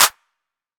YM Clap 13.wav